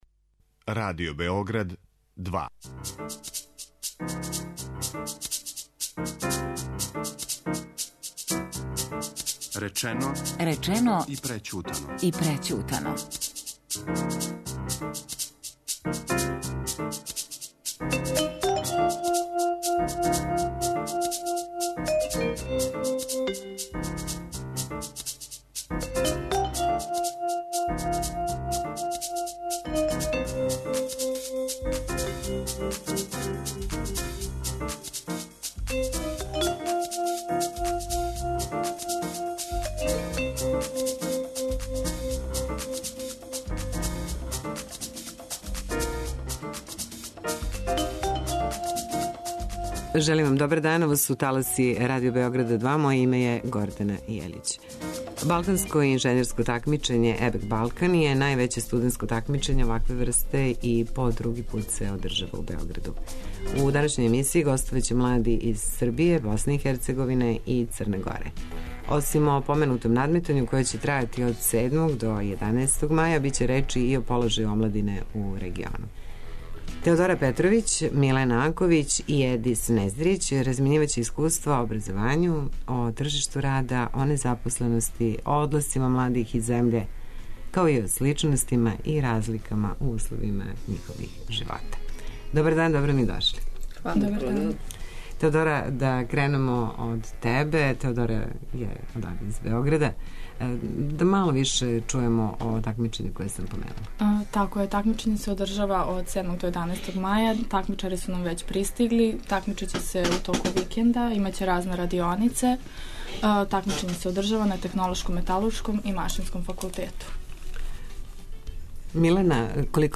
Балканско инжењерско такмичење ЕБЕЦ Балкан је највеће студентско такмичење овакве врсте и по други пут се одржава у Београду. У данашњој емисији гостоваће млади из Србије, Босне и Херцеговине и Црне Горе. Осим о поменутом надметању, које ће трајати од 7. до 11. маја, биће речи и о положају омладине у региону.